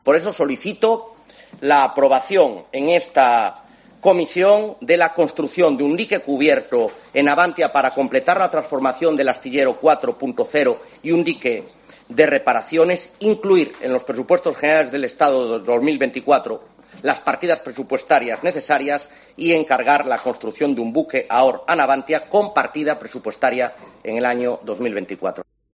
Rey Varela, a la izquierda, durante su comparecencia en la Cámara alta - FOTO: Senado